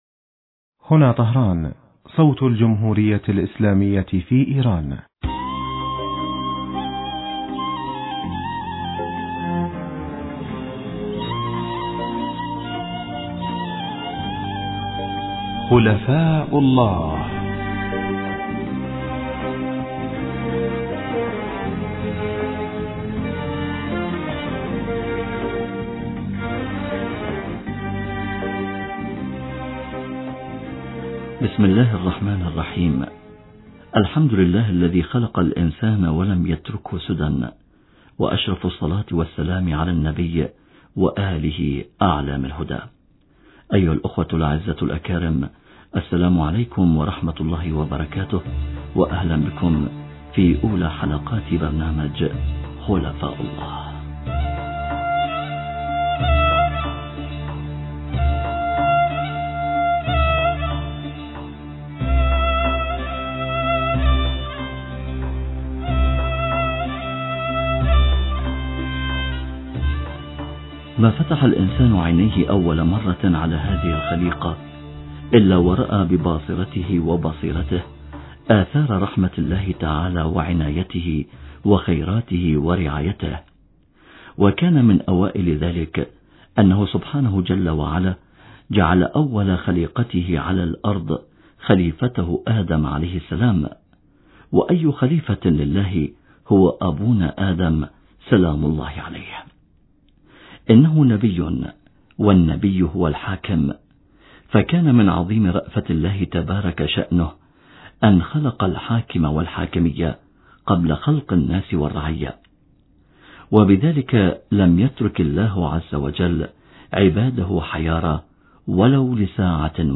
في الحديث الهاتفي التالي